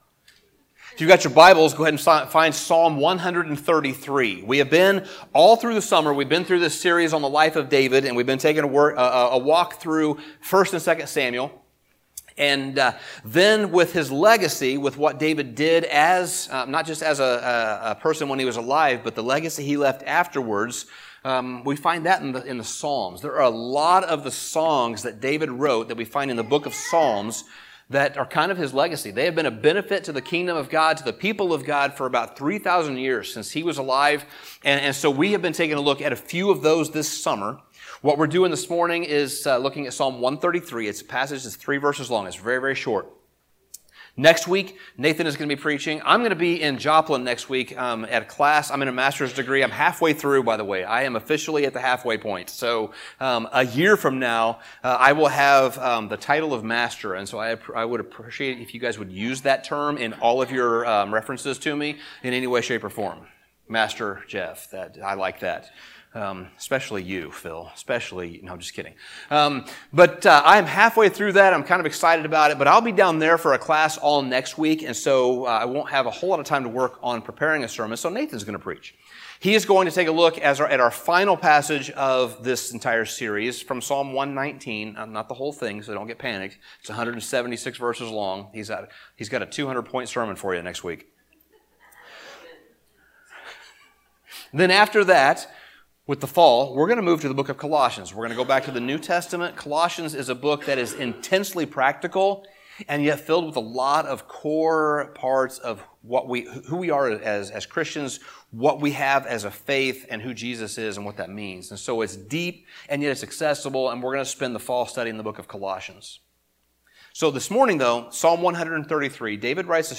Sermon Summary Near the end of the book of Psalms, we find a tiny psalm written by David about the importance of unity.